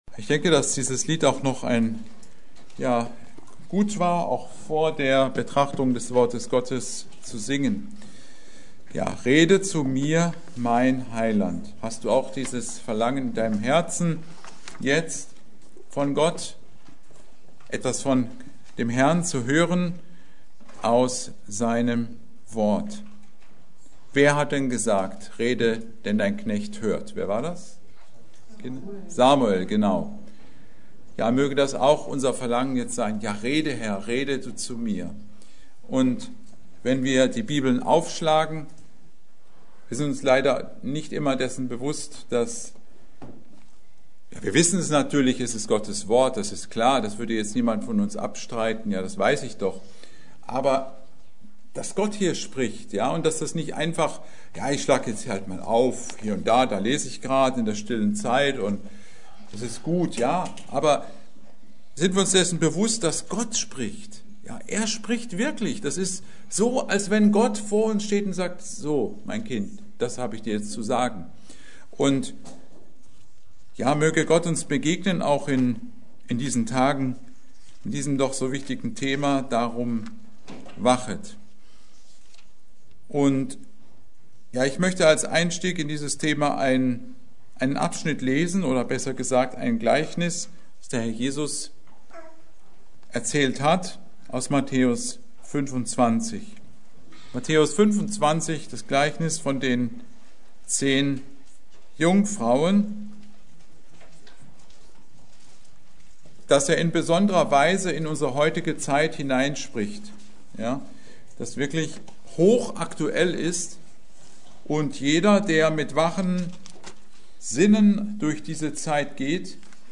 Predigt: Darum wacht!